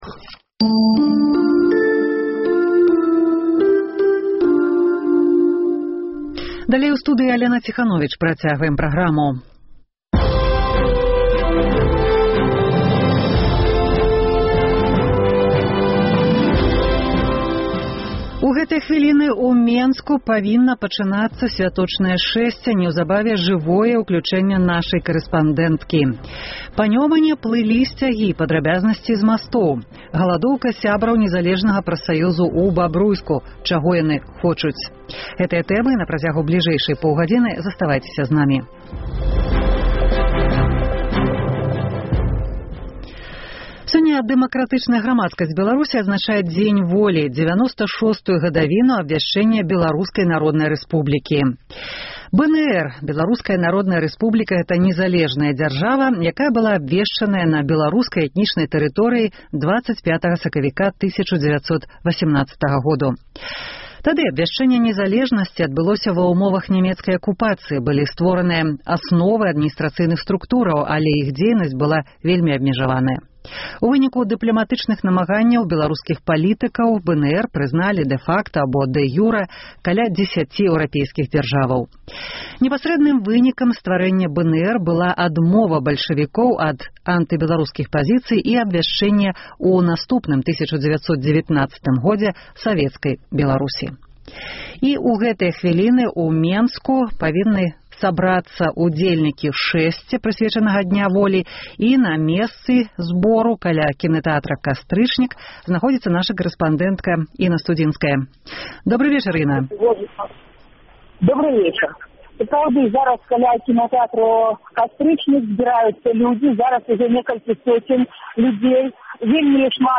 Двойчы на поўгадзіну — жывое ўключэньне нашай карэспандэнткі.